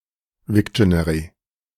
Das Wiktionary (IPA: [ˈvɪkʃəˌnɛʀi